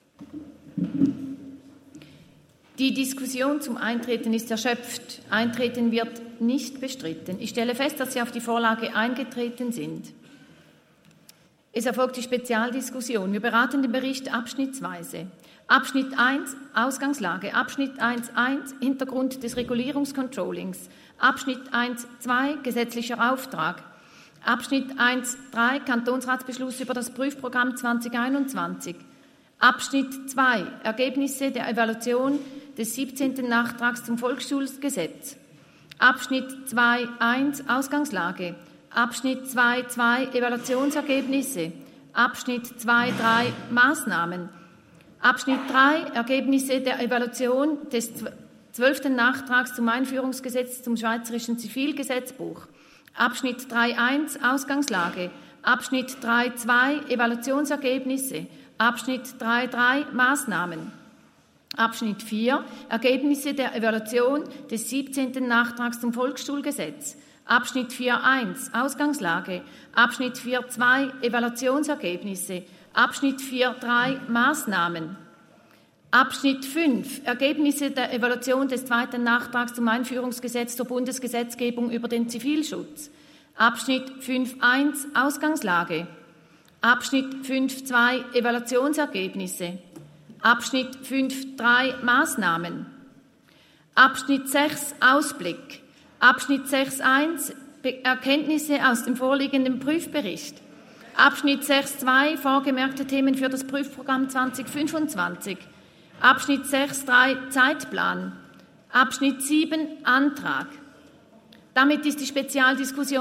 Session des Kantonsrates vom 18. bis 20. September 2023, Herbstsession
19.9.2023Wortmeldung
Schöb-Thal, Ratspräsidentin, stellt Eintreten auf die Vorlage fest.